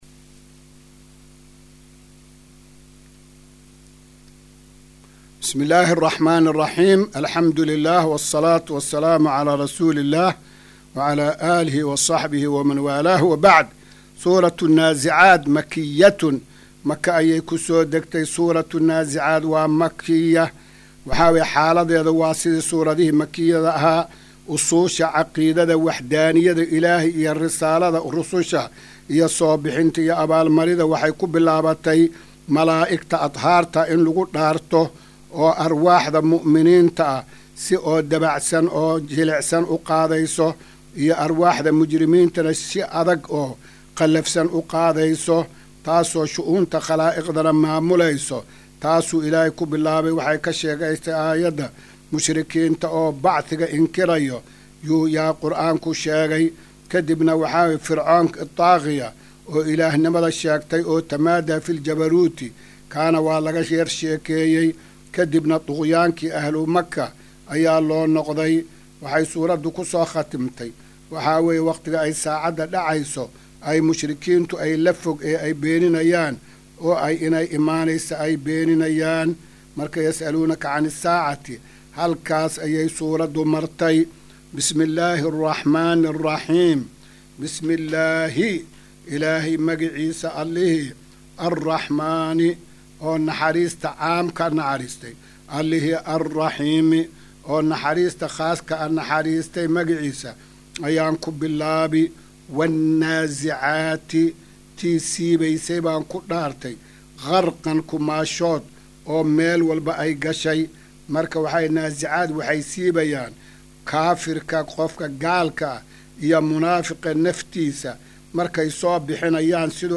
Casharka-279aad-ee-Tafsiirka.mp3